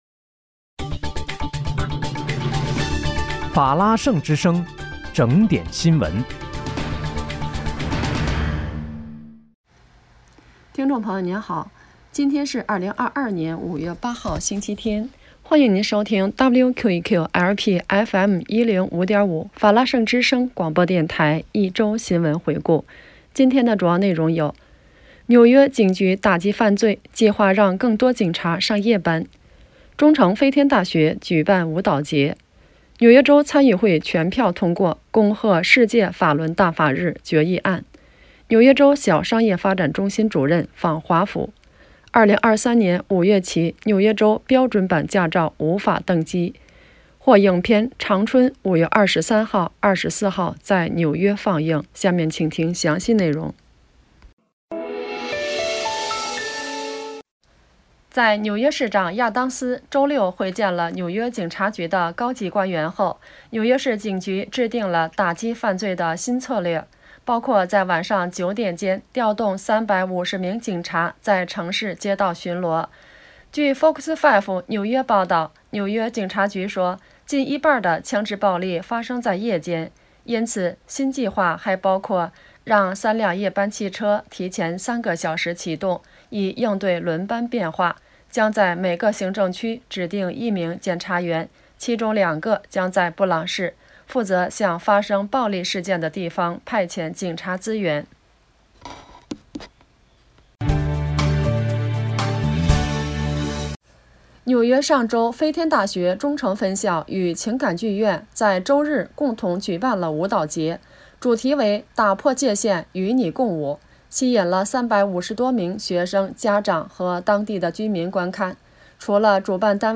5月8日（星期日）一周新闻回顾